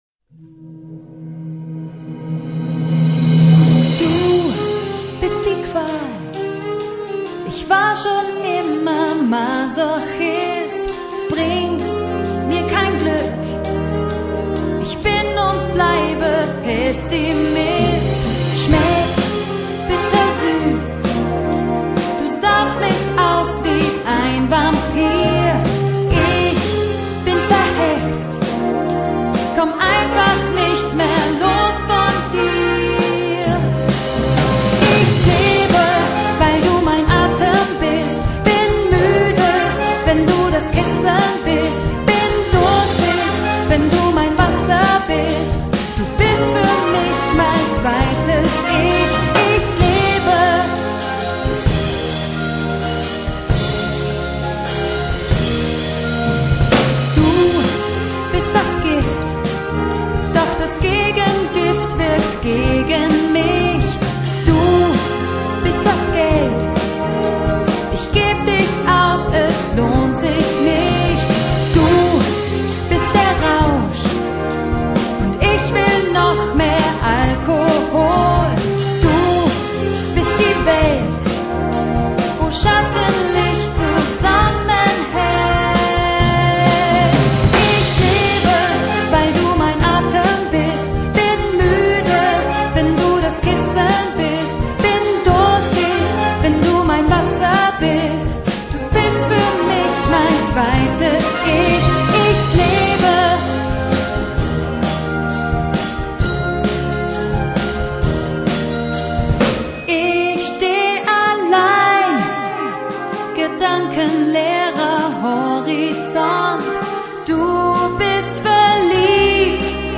Gattung: für Solo Gesang und Blasorchester
Besetzung: Blasorchester